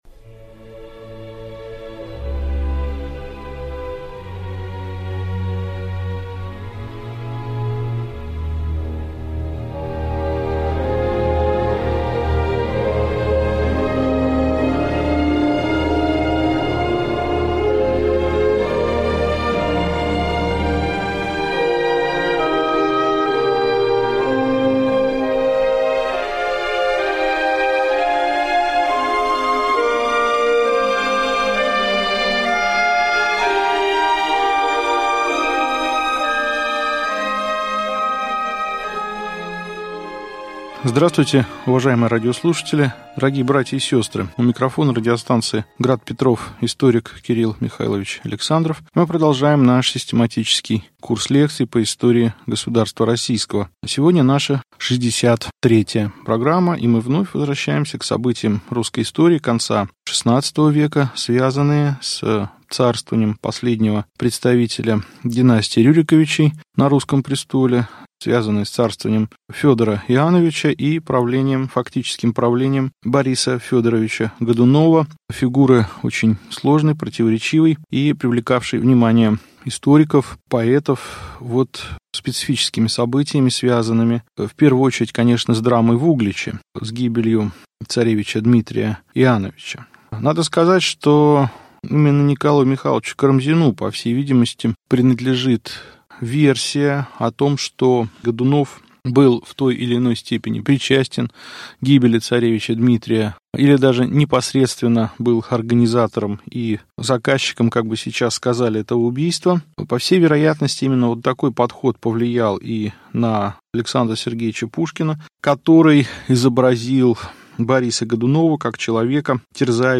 Аудиокнига Лекция 63. Гибель царевича Димитрия | Библиотека аудиокниг